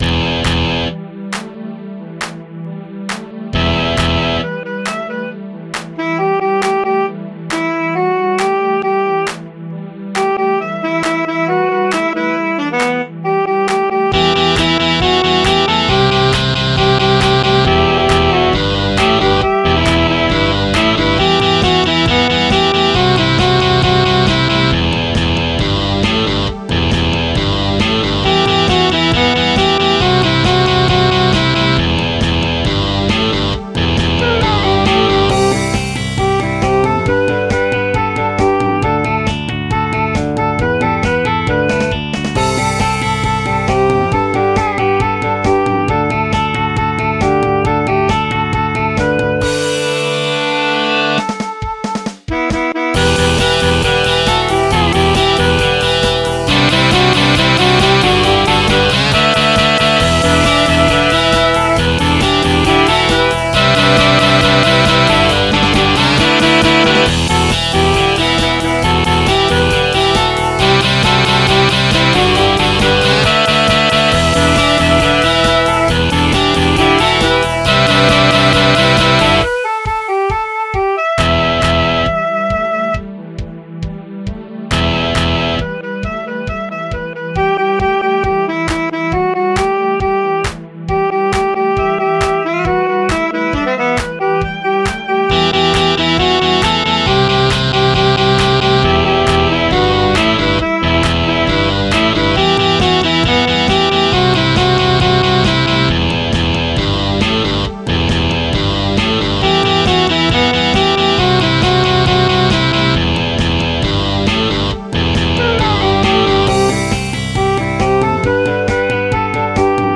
MIDI 45.91 KB MP3 (Converted) 3.4 MB MIDI-XML Sheet Music